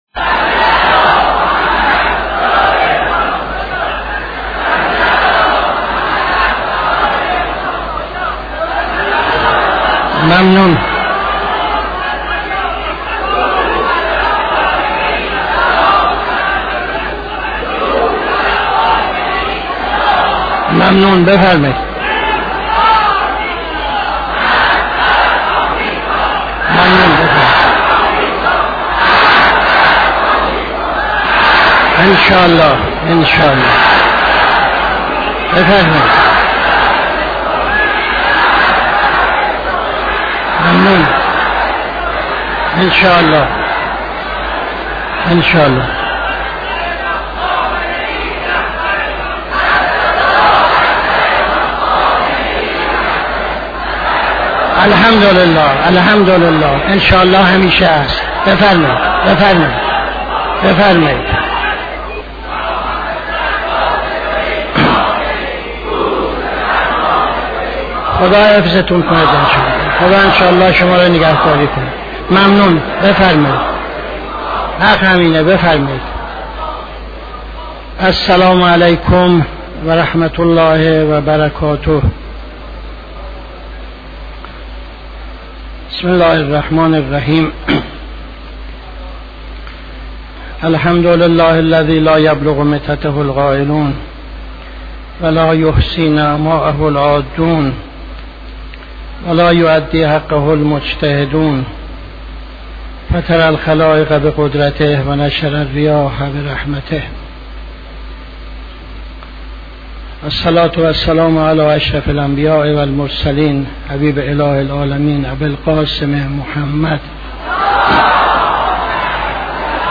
خطبه اول نماز جمعه 05-02-76